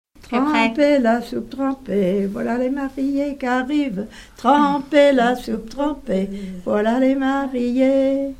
circonstance : fiançaille, noce
Pièce musicale inédite